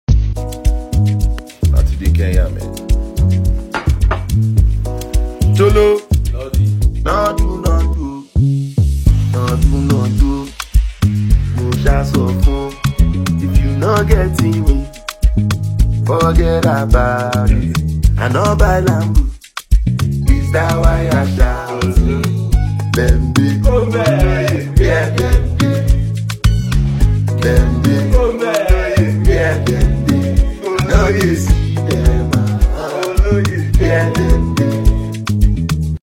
Talented Nigerian Singer